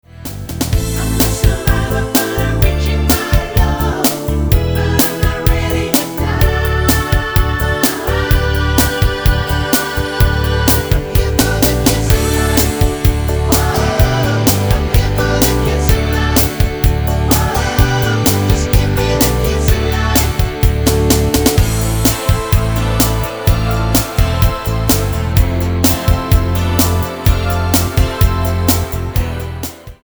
Tonart:Am mit Chor
Die besten Playbacks Instrumentals und Karaoke Versionen .